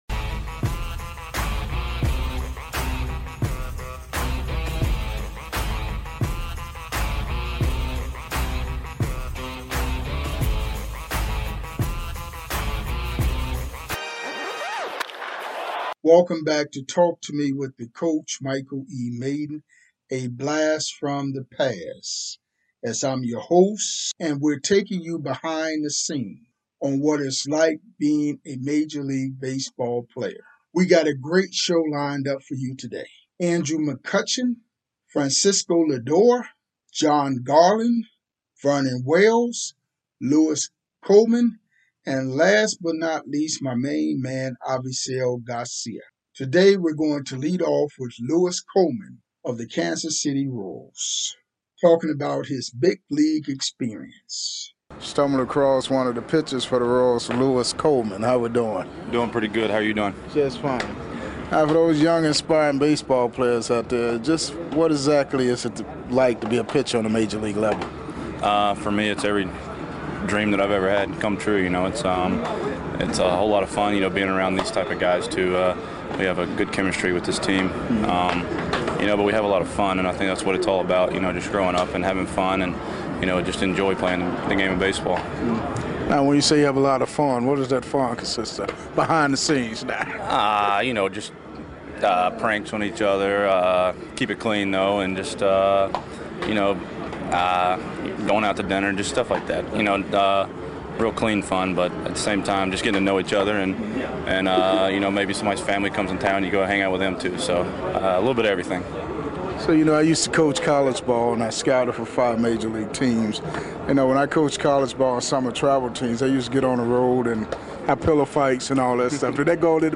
Classic Major League Baseball Interviews 105